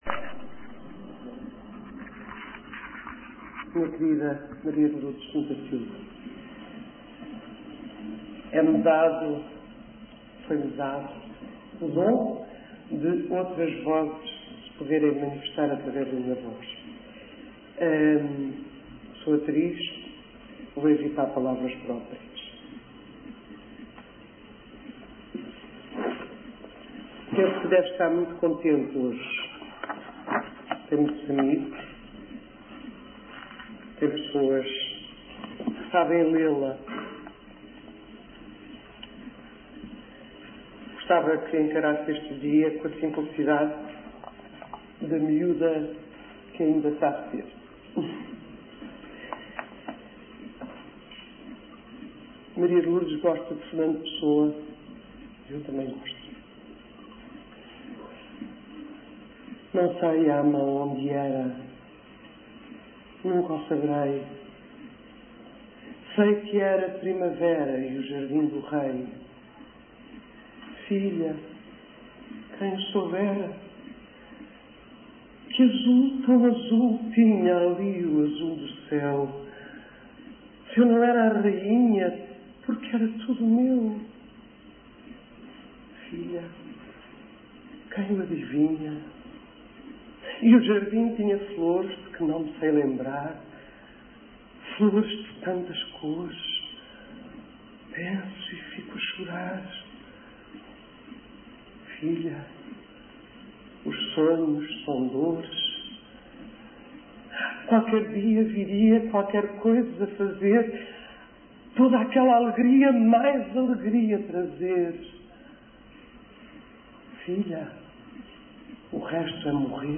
Leitura de poemas por Maria do Céu Guerra
Leitura de poemas pela atriz Maria do Céu Guerra para homenagear Maria de Lourdes Pintasilgo (poemas de Fernando Pessoa, Yvette Centeno, Fiama, Hélia Correia).